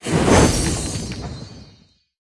Media:RA_Wizard_Evo.wav UI音效 RA 在角色详情页面点击初级、经典和高手形态选项卡触发的音效